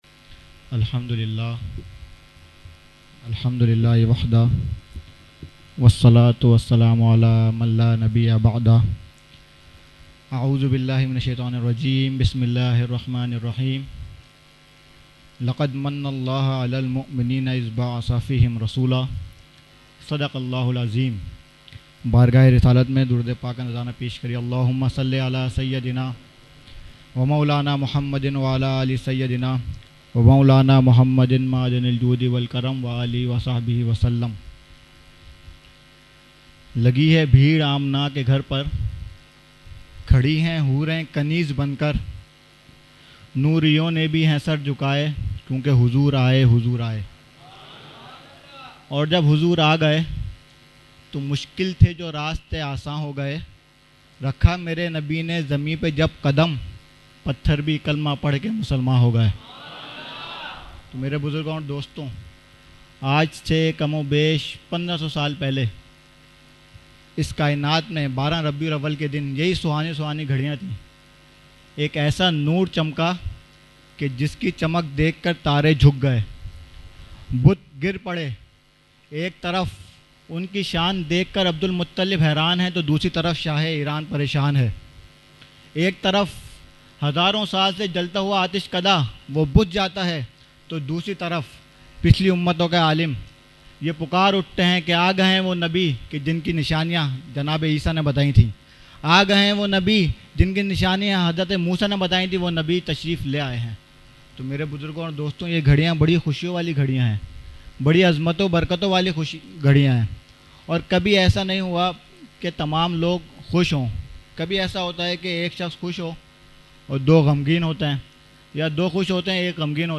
Mehfil e Jashne Subhe Baharan held on 28 September 2023 at Dargah Alia Ashrafia Ashrafabad Firdous Colony Gulbahar Karachi.
Category : Speech | Language : UrduEvent : Jashne Subah Baharan 2023